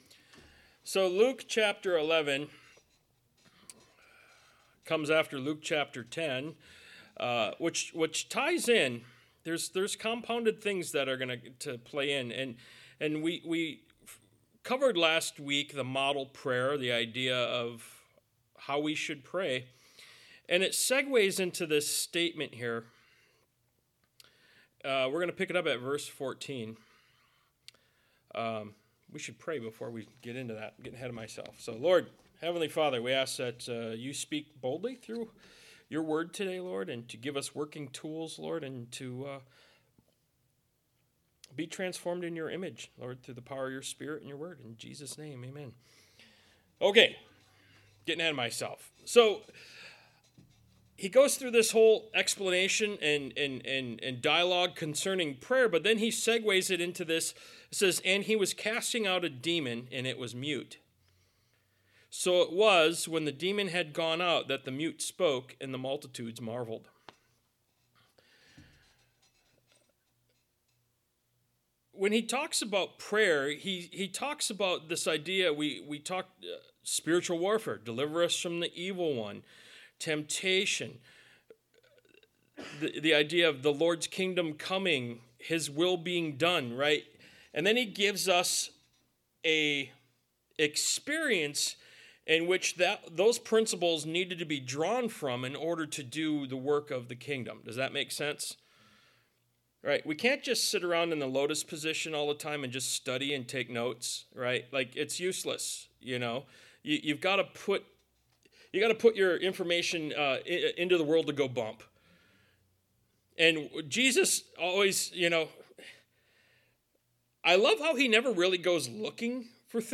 Ministry of Jesus Service Type: Sunday Morning « “Teach Us to Pray” Ministry of Jesus Part 63 “Are You Blessed” Ministry of Jesus Part 65 »